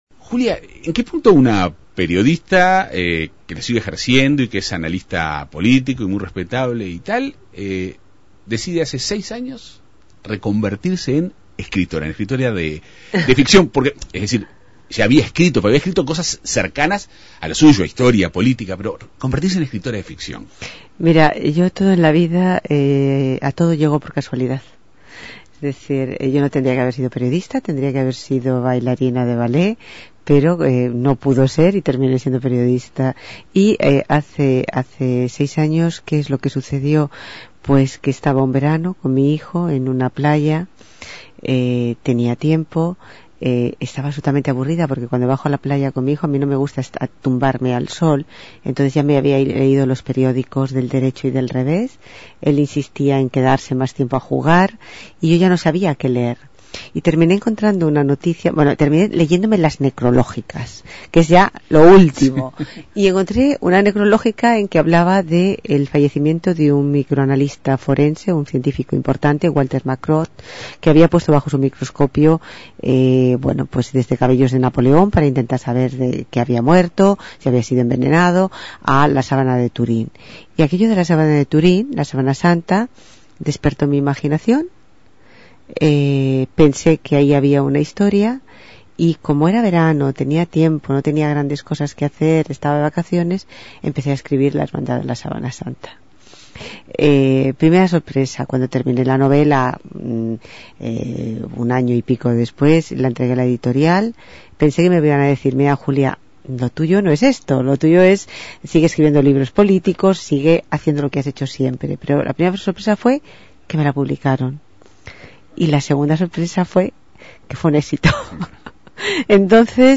Esta semana, la escritora española Julia Navarro llegó a Montevideo para presentar su última novela, Dime Quién soy. Un libro en el que retrata la memoria del siglo XX, desde la Segunda República Española a la Guerra Fría, a través de personas anónimas que protagonizaron esta historia. La escritora conversó en la Segunda Mañana de En Perspectiva.